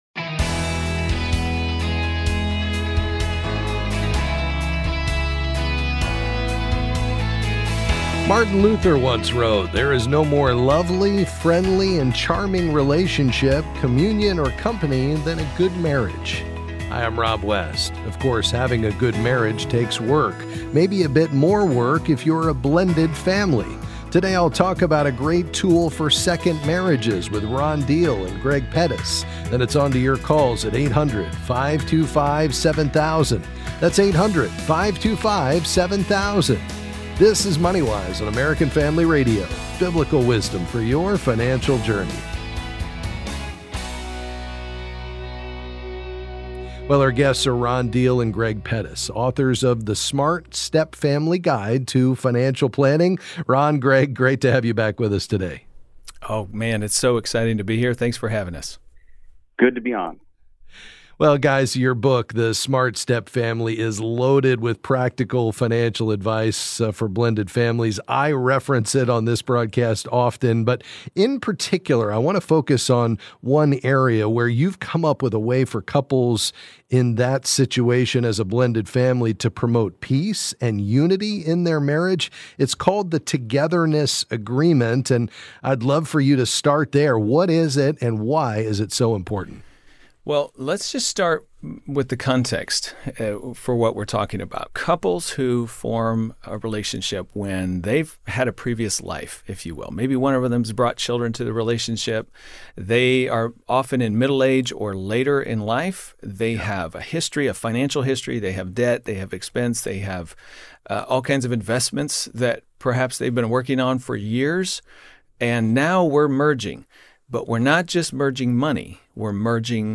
Then we'll open our phone lines for your questions on any financial topic.